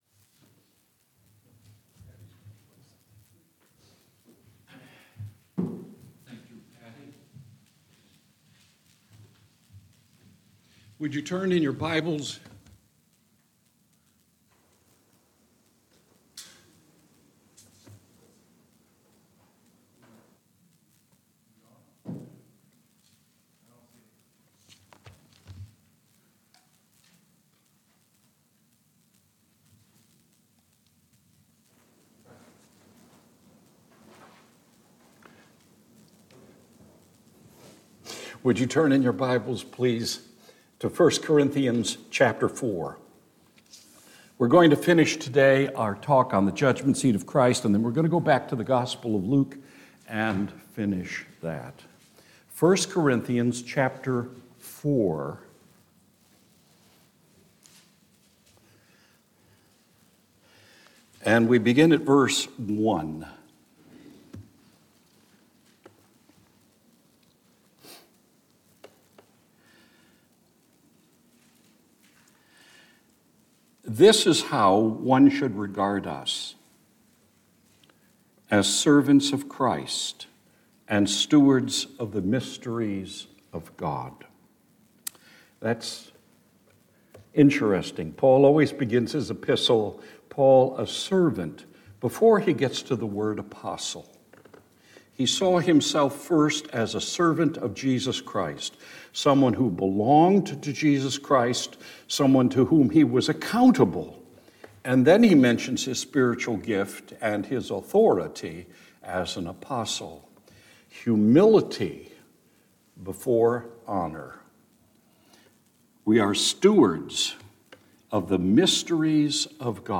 Individual non-series sermons